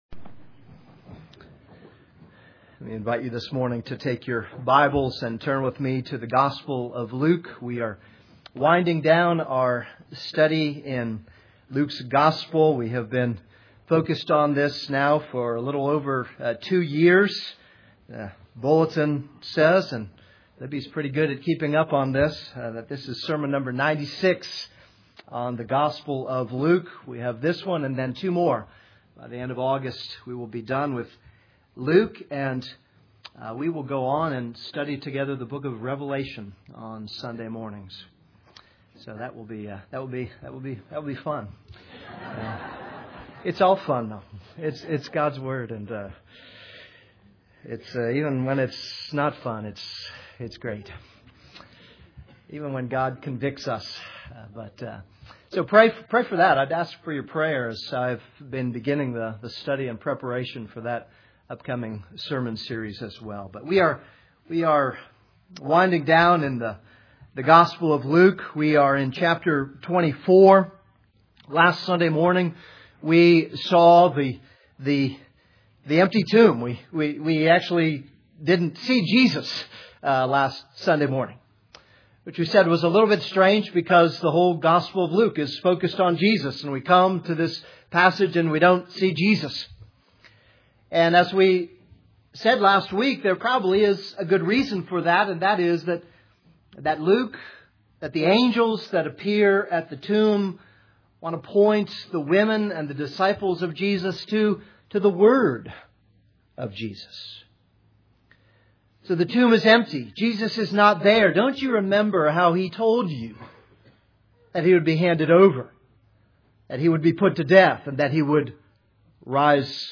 This is a sermon on Luke 24:13-35.